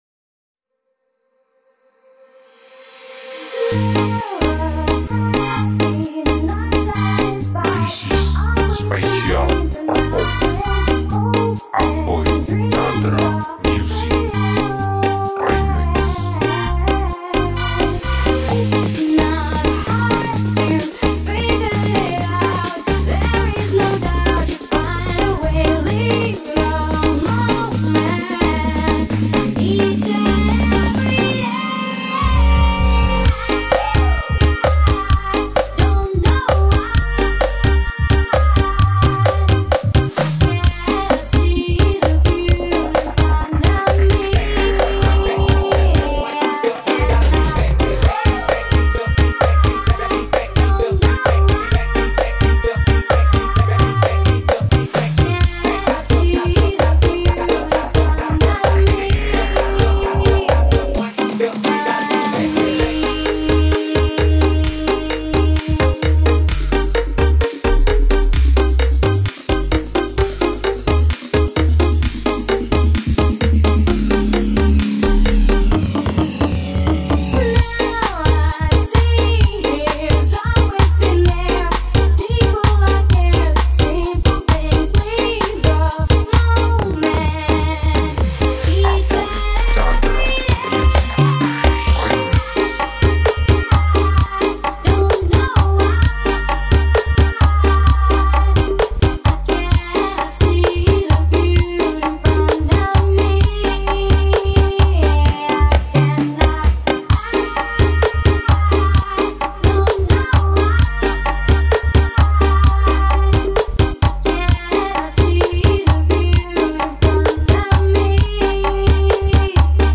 Full Bass Nguk Party Mengkane Wasek x Melody